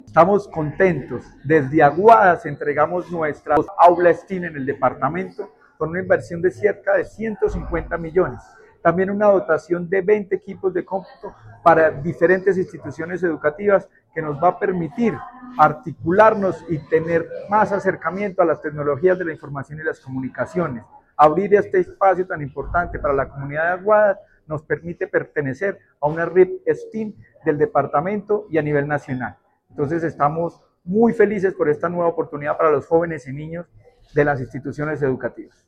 Durante el evento de apertura y entrega, el secretario de Educación de Caldas, Luis Herney Vargas Barrera, resaltó la importancia de las inversiones en herramientas tecnológicas y en espacios de aprendizaje como el aula STEM, que pueden aprovechar todas las instituciones del municipio.
Secretario de Educación de Caldas, Luis Herney Vargas Barrera.
Secretario-de-Educacion-Luis-Herney-Vargas-Aula-STEM-Aguadas.mp3